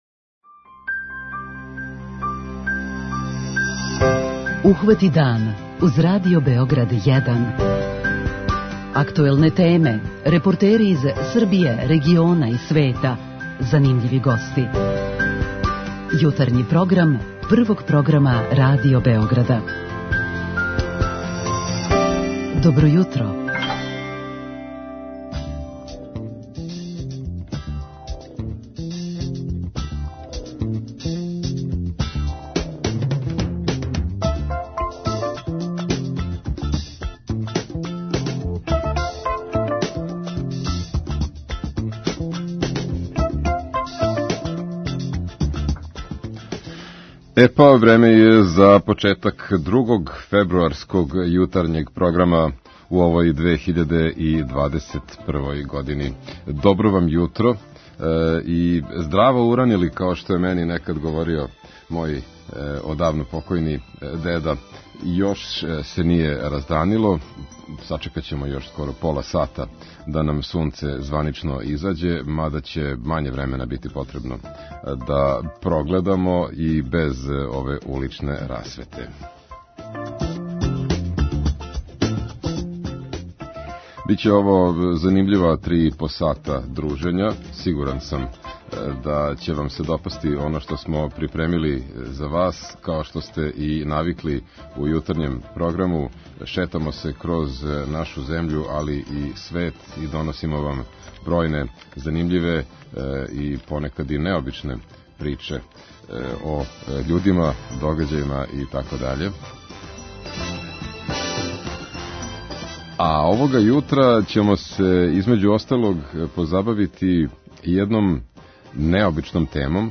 О овој теми разговараћемо и са слушаоцима у нашој рубрици 'Питање јутра'.